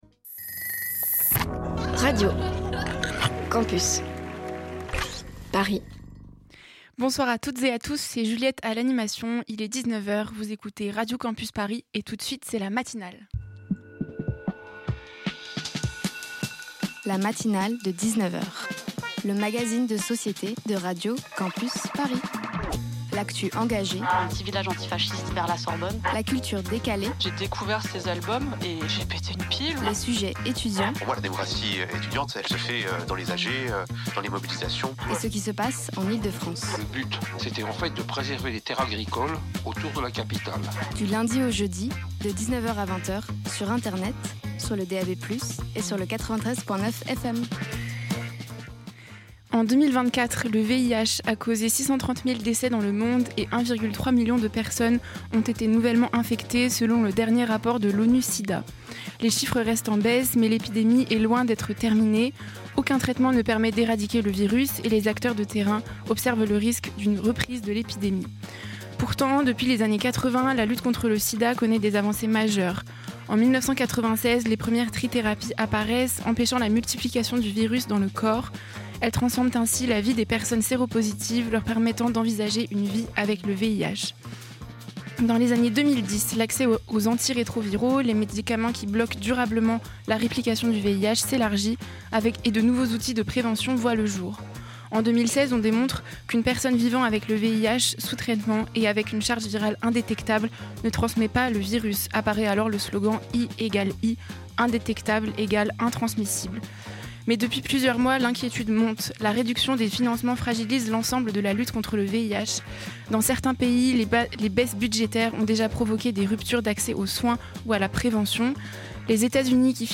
La quotidienne à l'heure de l'apéro
Type Magazine Société Culture